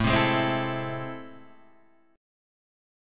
A Minor Major 7th